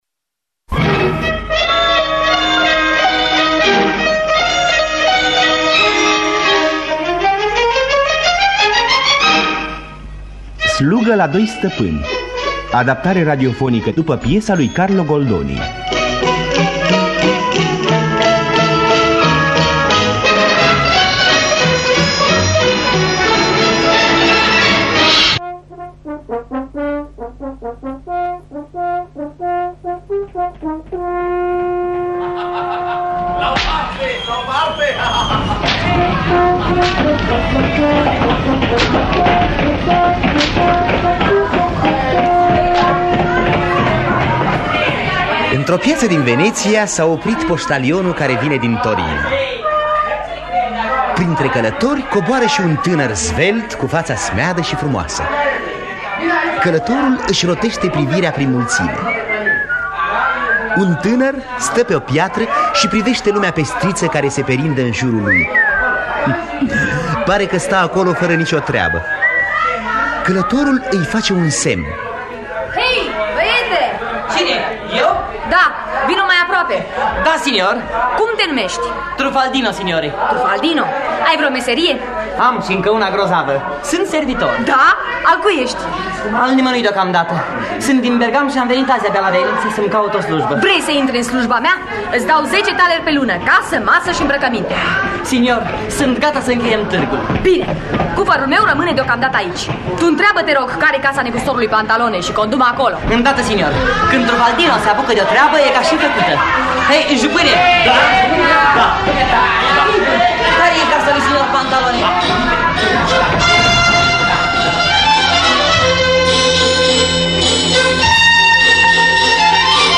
„Slugă la doi stăpâni” de Carlo Goldoni – Teatru Radiofonic Online